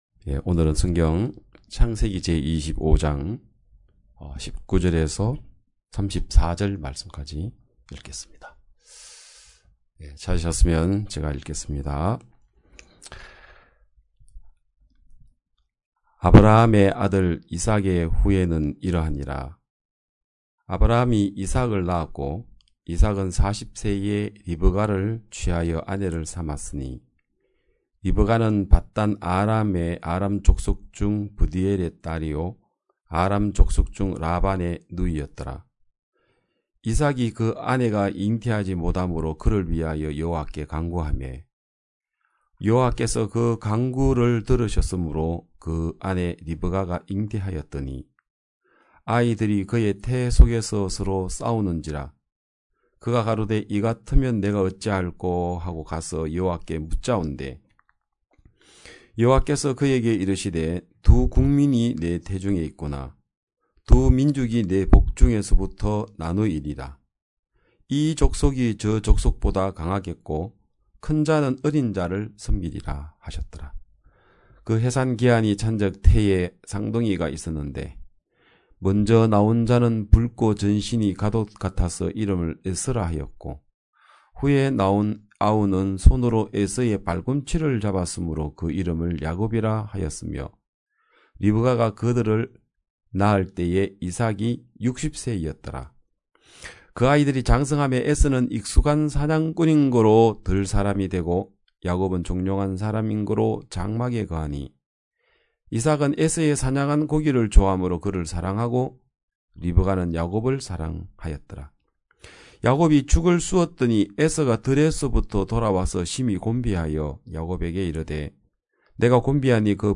2022년 4월 10일 기쁜소식양천교회 주일오전예배
성도들이 모두 교회에 모여 말씀을 듣는 주일 예배의 설교는, 한 주간 우리 마음을 채웠던 생각을 내려두고 하나님의 말씀으로 가득 채우는 시간입니다.